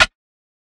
OZ-Snare (Bitchin).wav